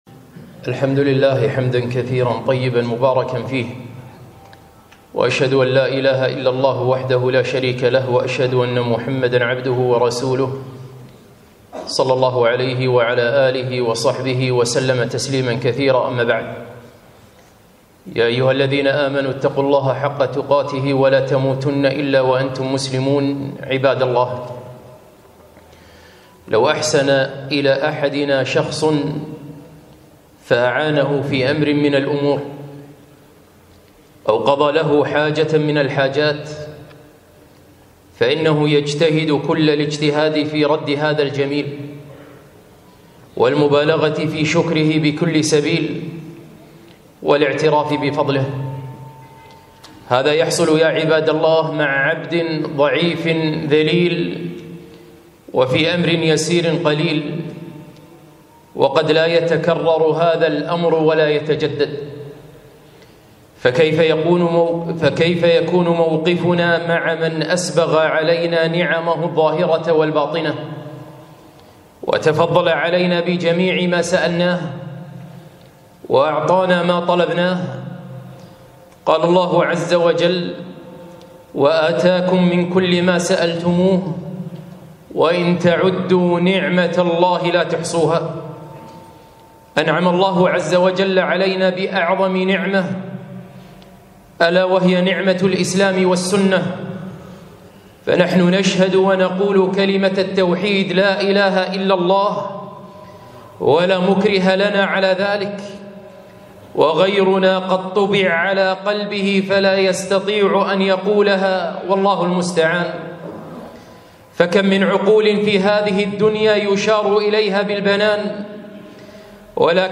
خطبة - لا تغفلوا عن الشكر